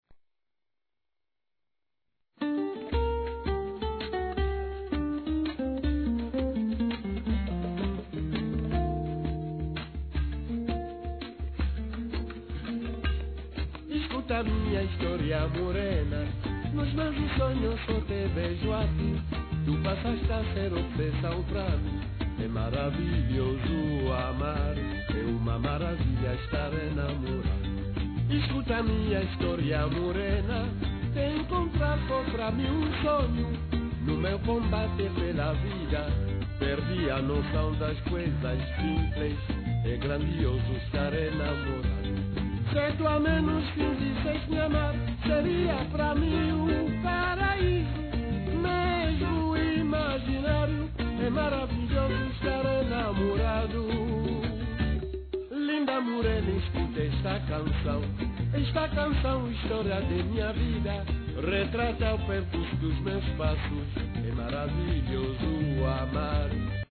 music is gentle, yet filled with passion.